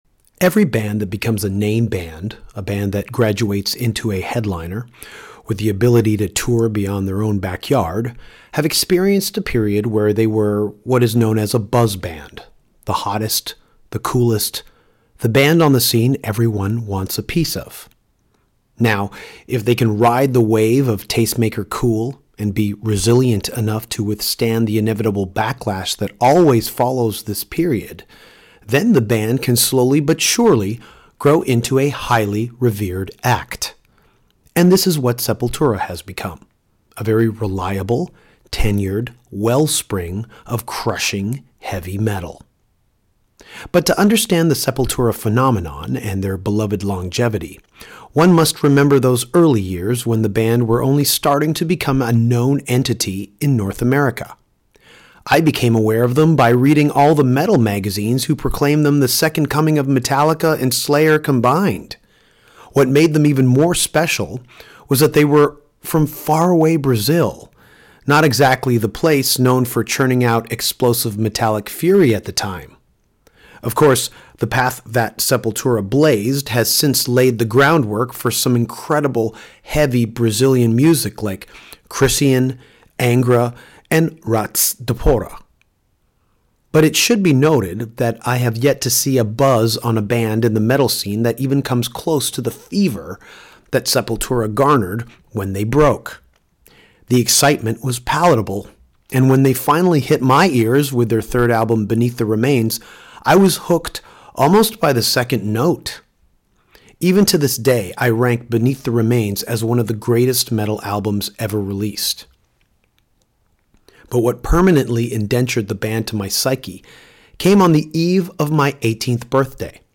Derrick Green, singer of Sepultura, met up with Danko, while both were on tour, to talk about John Joseph & The Cro-Mags, vegetarianism, Sammy Siegler, DVDASA, Sam Spiegel & Maximum Hedrum.